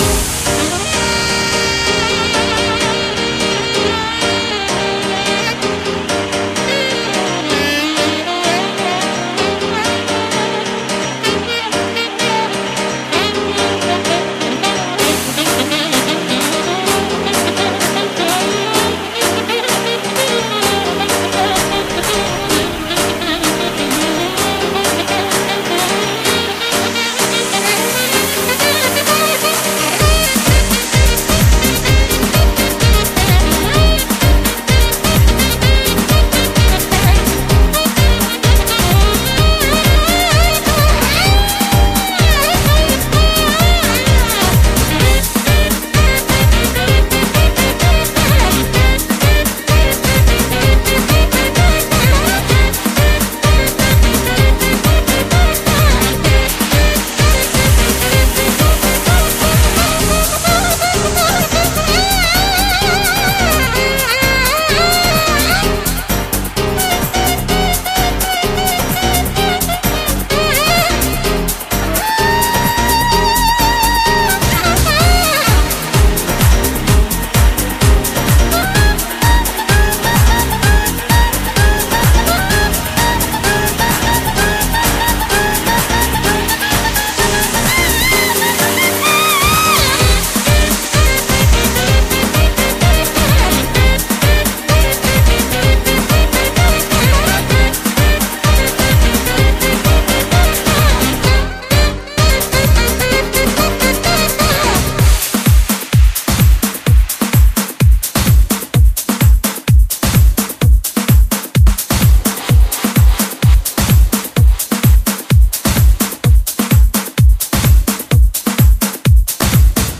BPM128
Audio QualityPerfect (High Quality)
Comments[JAZZ HOUSE]